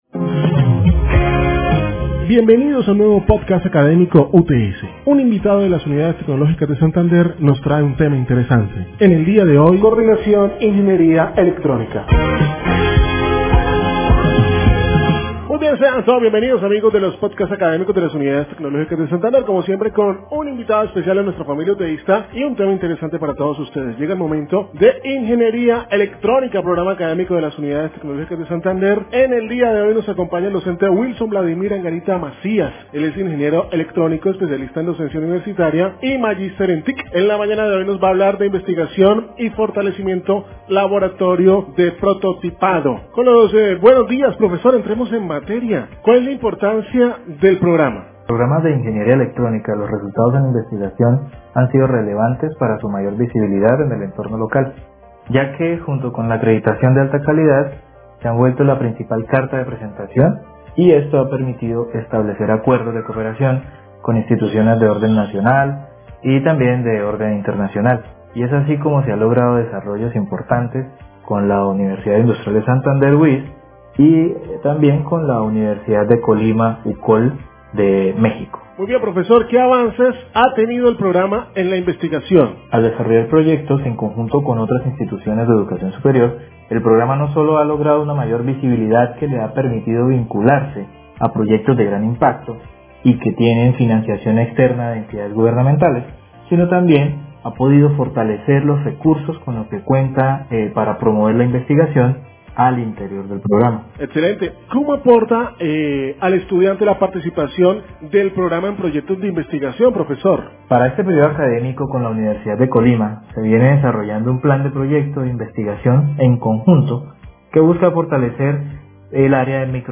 Ingeniería Electrónica UTS Pódcast